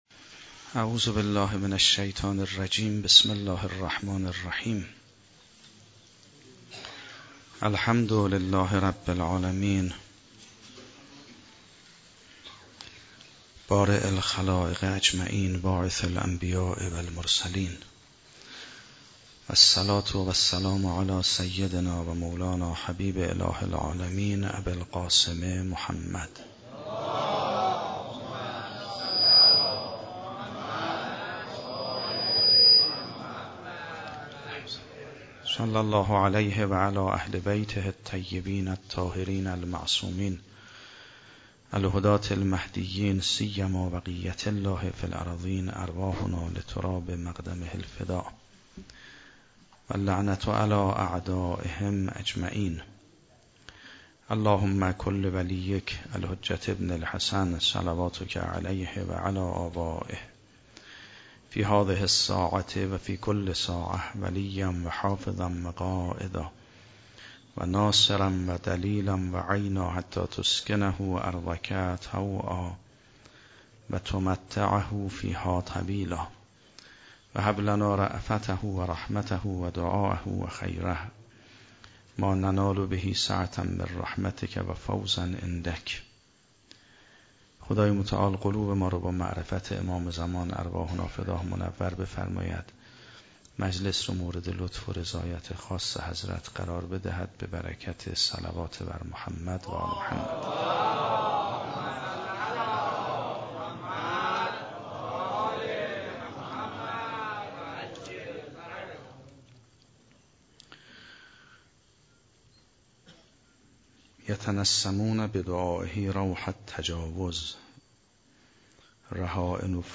روز پنجم محرم 96 - حسینیه حضرت زهرا - سخنرانی